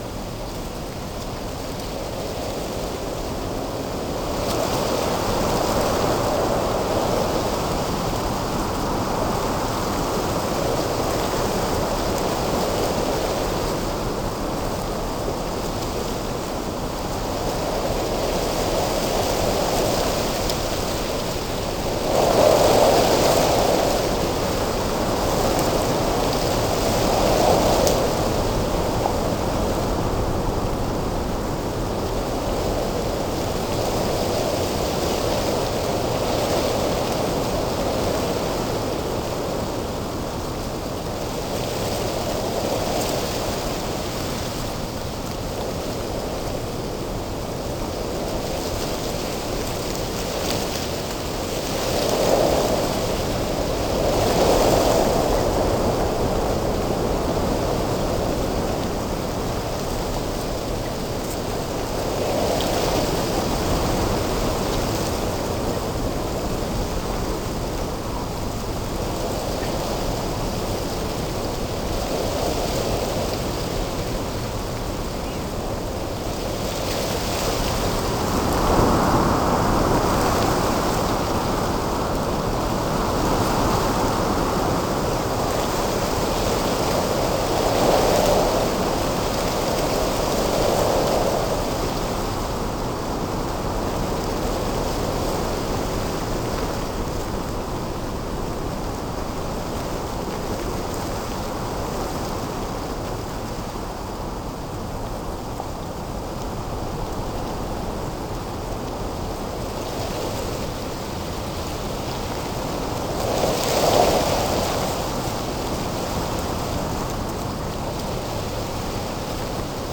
treegrove_mono_01.ogg